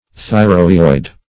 Meaning of thyrohyoid. thyrohyoid synonyms, pronunciation, spelling and more from Free Dictionary.
Search Result for " thyrohyoid" : The Collaborative International Dictionary of English v.0.48: Thyrohyoid \Thy`ro*hy"oid\, a. (Anat.)